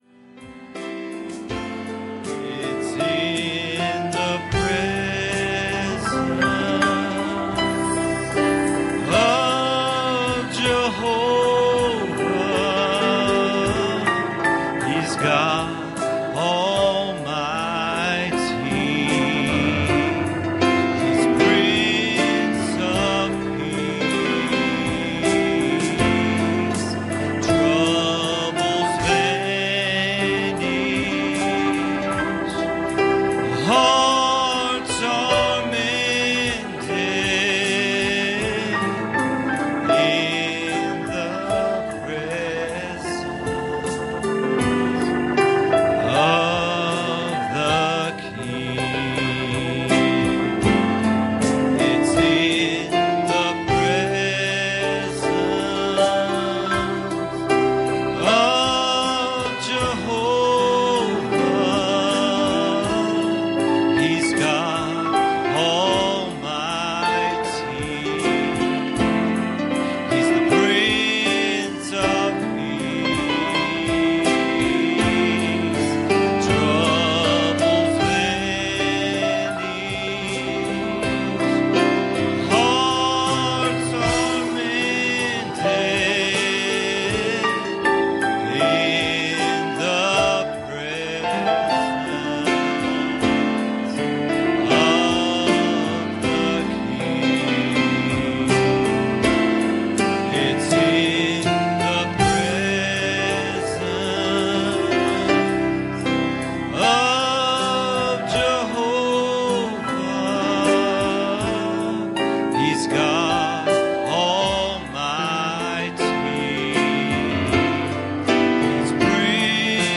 Series: Sunday Morning Services
Service Type: Sunday Morning